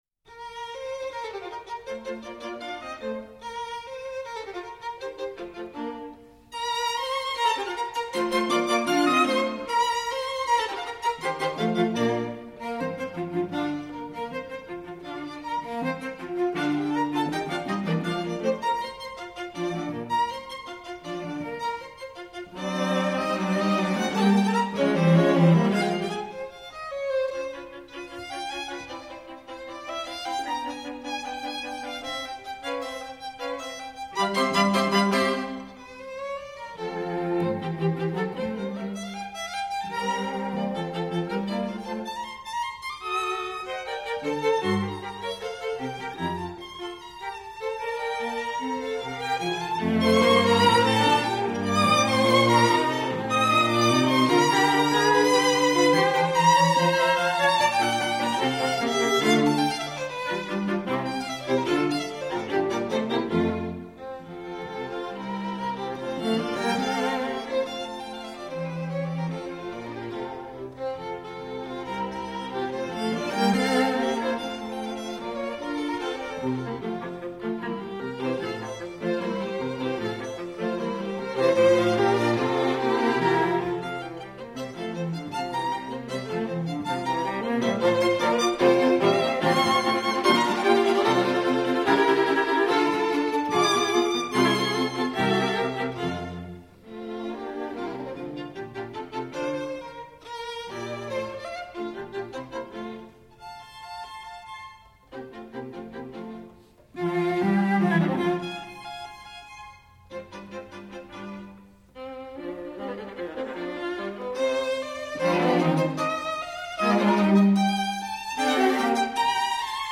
String Quartet in B flat major "The Hunt"
Allegro assai